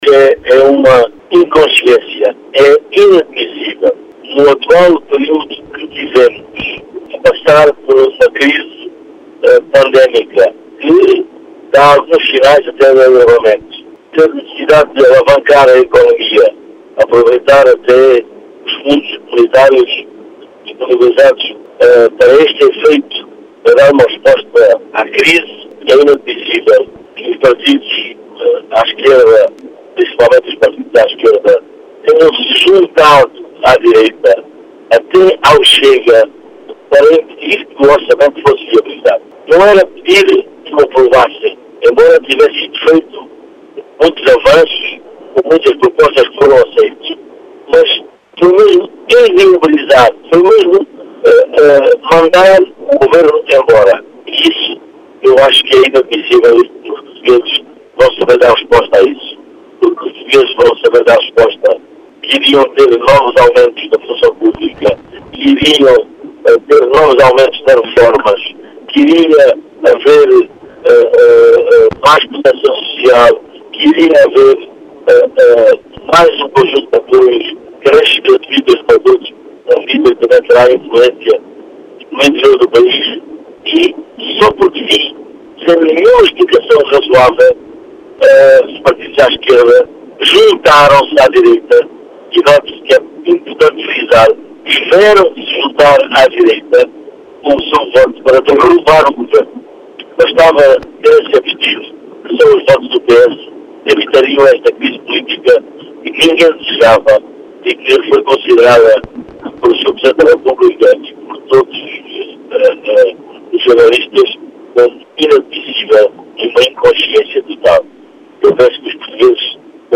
Em declarações à Rádio Vidigueira, o deputado socialista eleito por Beja, Pedro do Carmo, afirma que o chumbo do Orçamento é “inadmissível e uma inconsciência”, acusando os partidos da Esquerda de “sem nenhuma explicação razoável” se juntarem à direita.